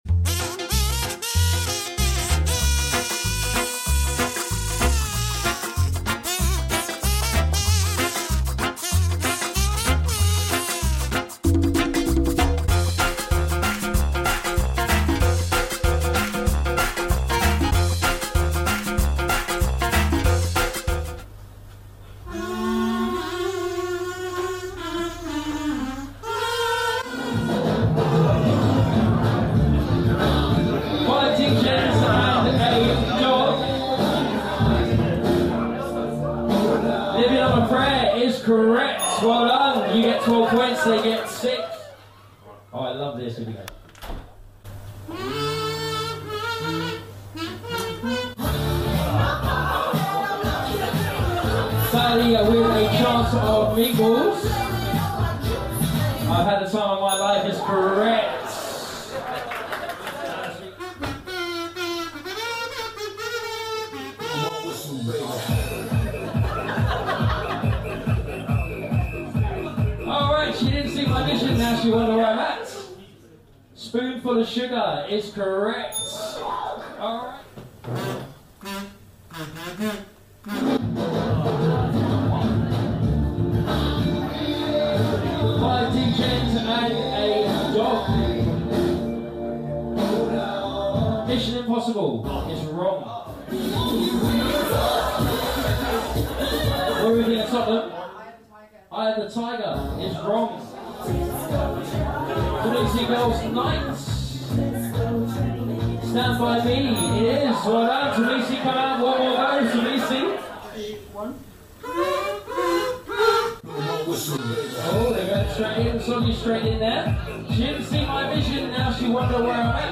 The kazoo music round saw every player being given a kazoo.